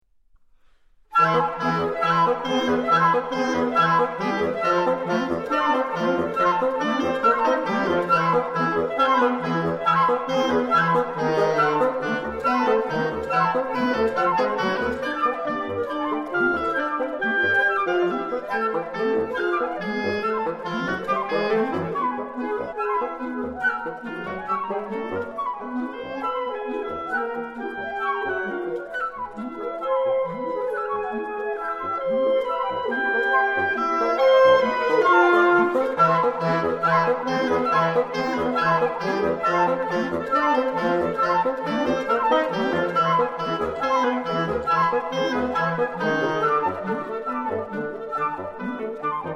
clarinette, basson et cor
I Andante tranquillo
IV Tempo di marcia francese